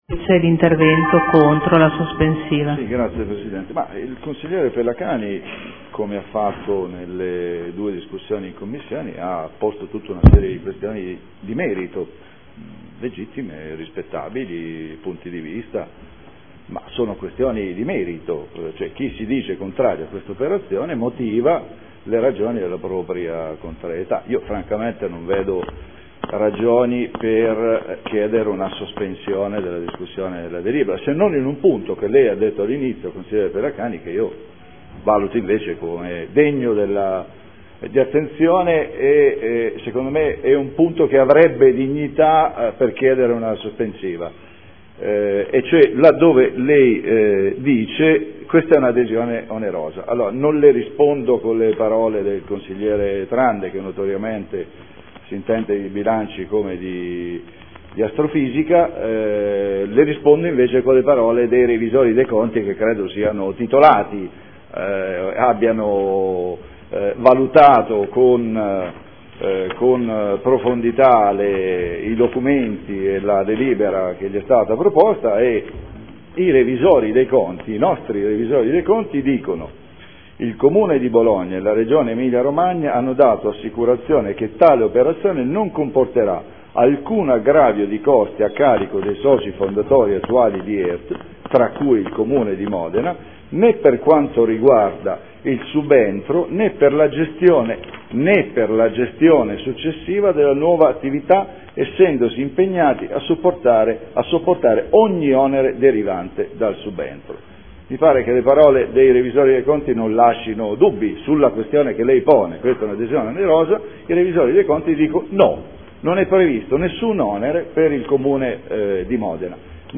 Paolo Trande — Sito Audio Consiglio Comunale
Seduta del 23/01/2014 Adesione del Comune di Bologna a Emilia Romagna Teatro Fondazione in qualità di socio fondatore necessario. Intervento contro sospensiva.